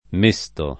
m%Sto] — con -e- chiusa nelle forme rizotoniche, per regolare continuaz. dell’-i- breve del lat. miscere «mescolare» e del suo part. pass. mixtus — cfr. mesto